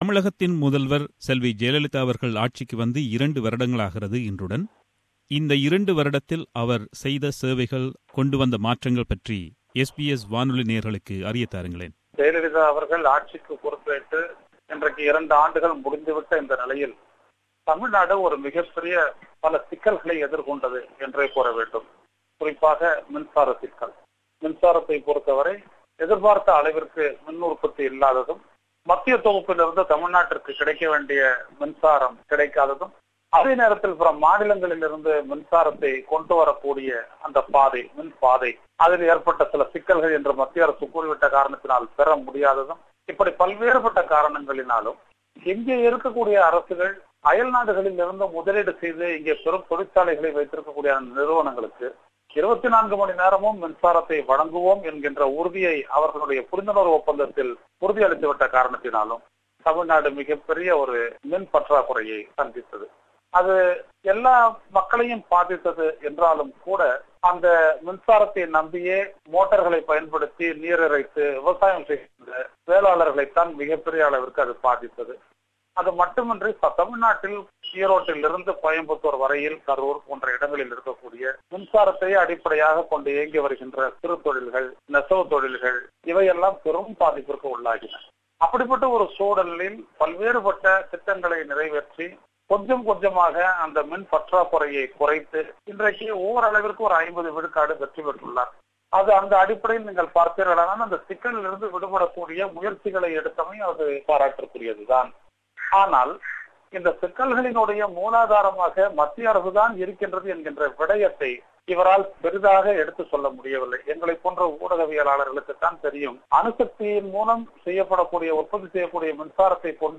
நேர்காணலின் முழுப்பகுதி.